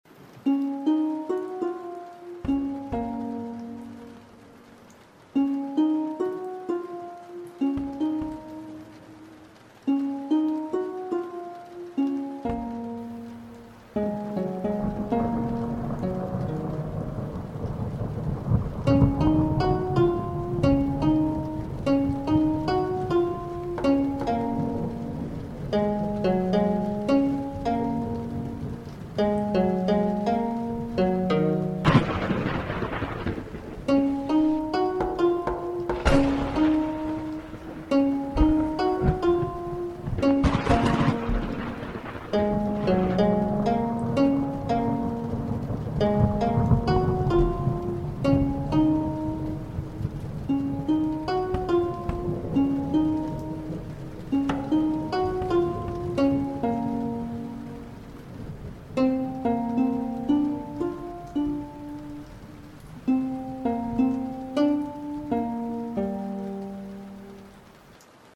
用Fl花两个小时做的劣质音乐，可以用作雷雨环境。